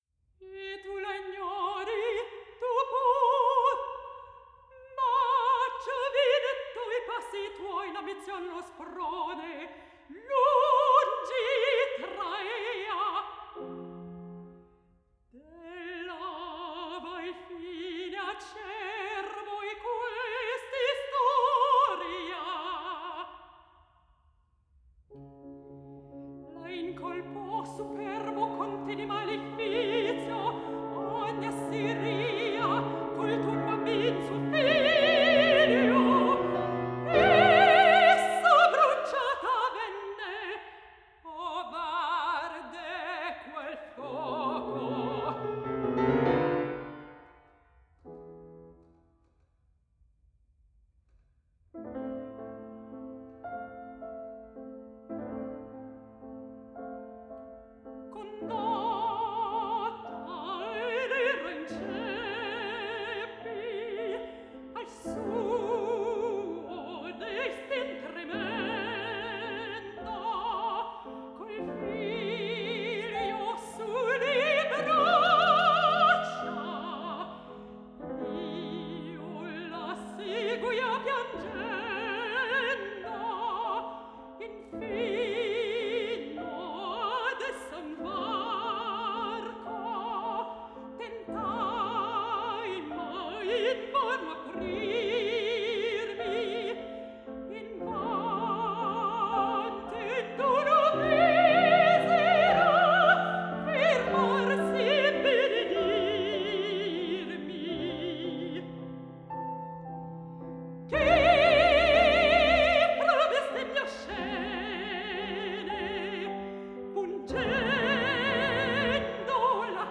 mezzo-soprano
Piano